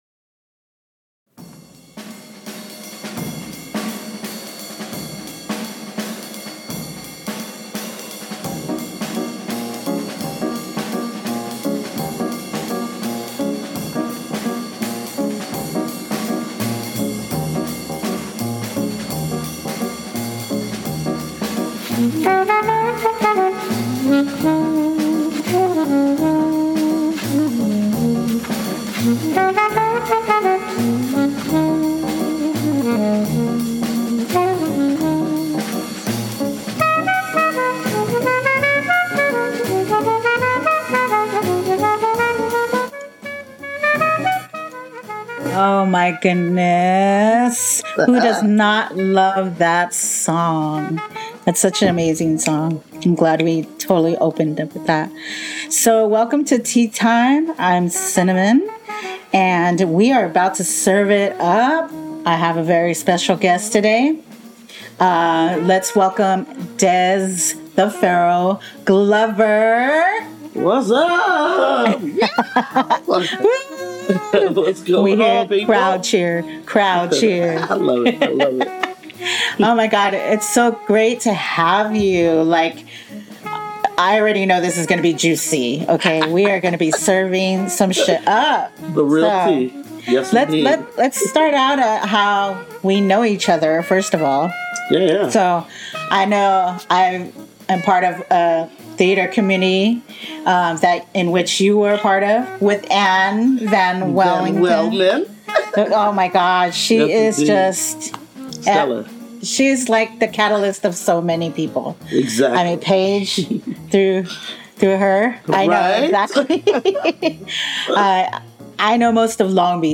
This episode aired live on CityHeART Radio on Tuesday July 18, 2023 at 7pm.